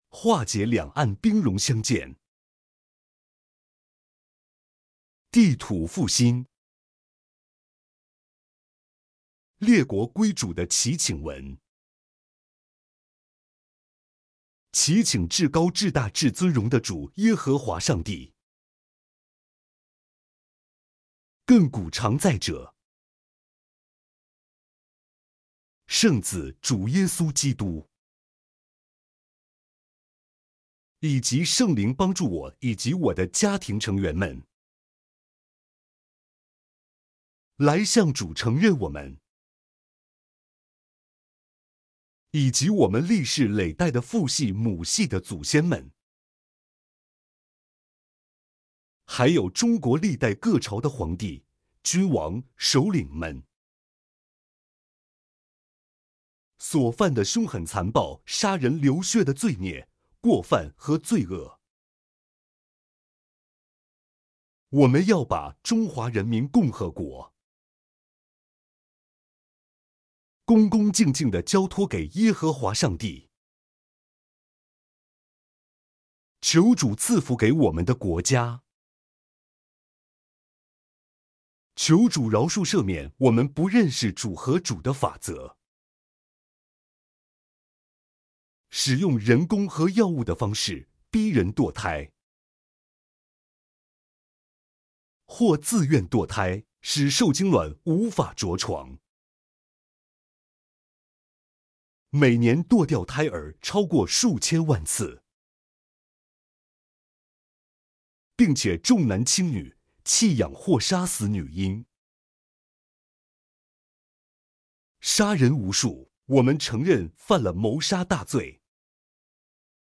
两岸合一相爱祈福文（男声领读版）.mp3